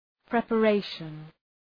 Προφορά
{,prepə’reıʃən}